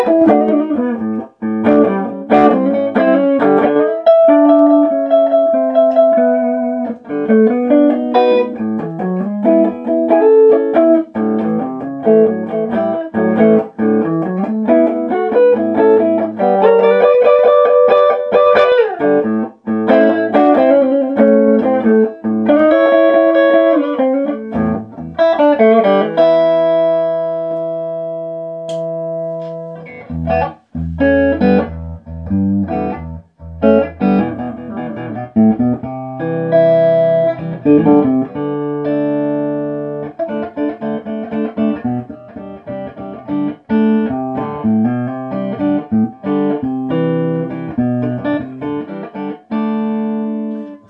Uusi 2-kanavainen Rikstone H15 VM nuppi, jossa toinen kanava pohjautuu VOX AC15 EF86-kanavaan ja toinen Matchless Spitfireen.
Soundinäytteet (Gibson Les Paul äänitetty älypuhelimella):